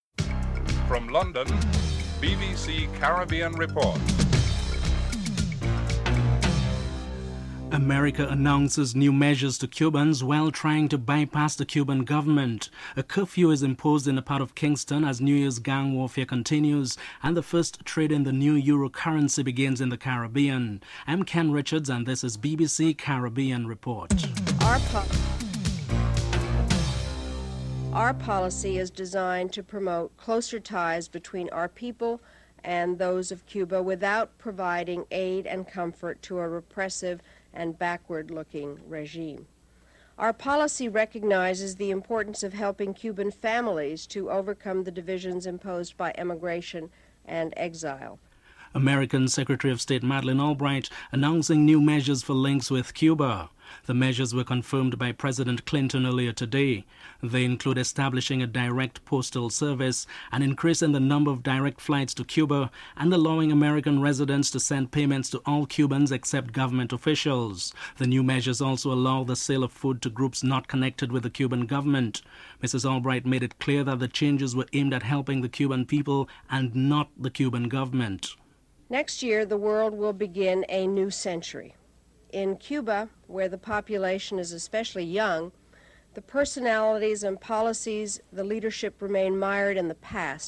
1. Headlines (00:00–00:26)
2. American Secretary of State, Madeleine Albright, announce new measures for links with Cuba. Madeleine Albright speaks.